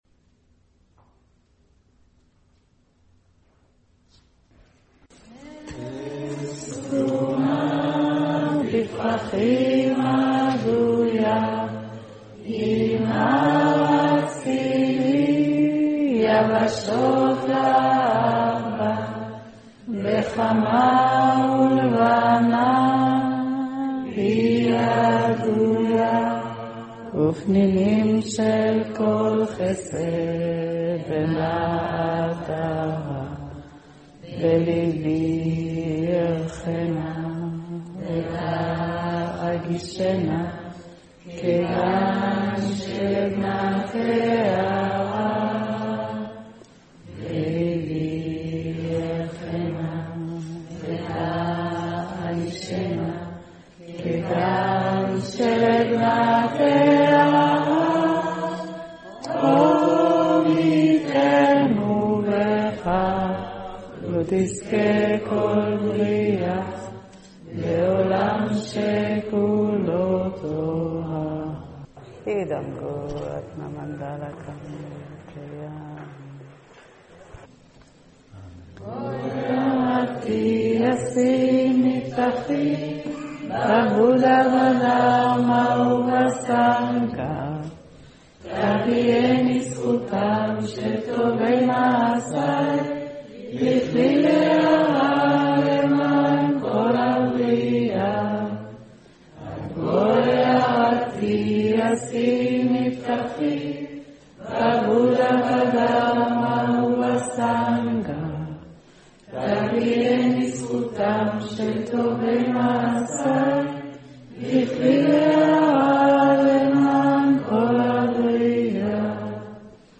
במרכז רוחני ערבה